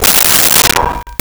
Metal Lid 04
Metal Lid 04.wav